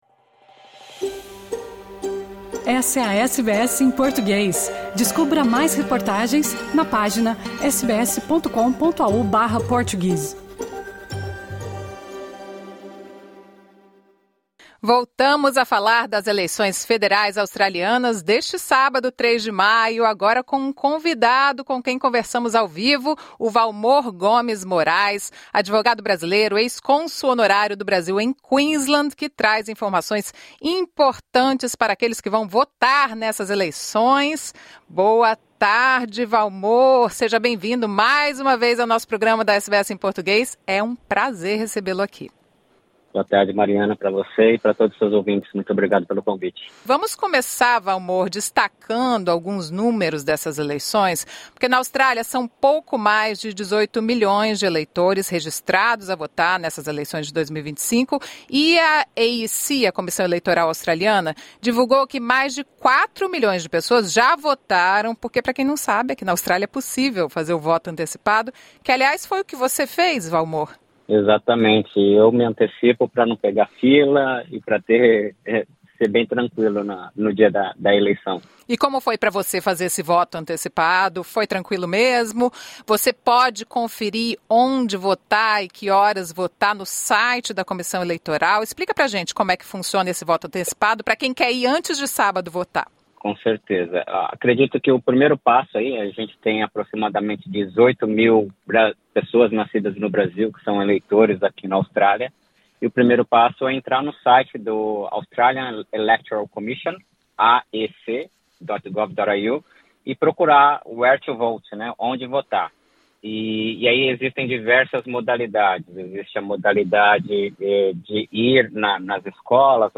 Conversamos com o advogado brasileiro